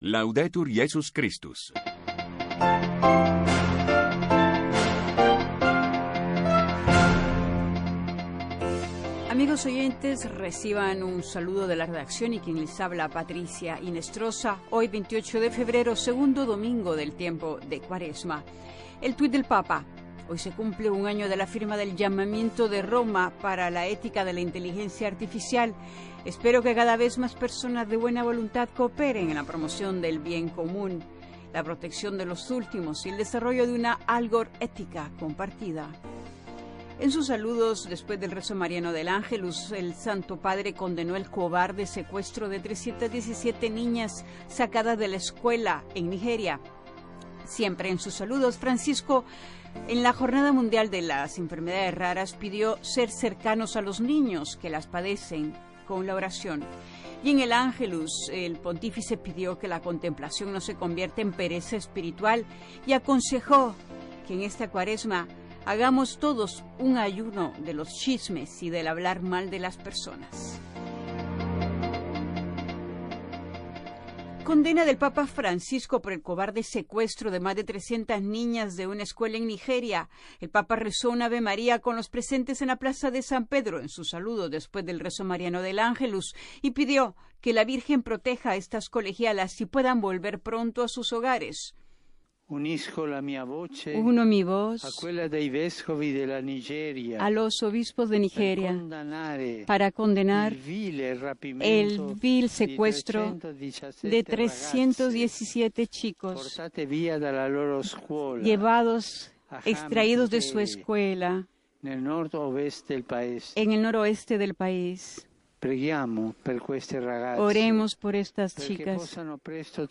Radio Vaticana. L'informatiu en castellà de Ràdio Vaticana. Tota l'activitat del pontífex, com també totes aquelles notícies de Roma.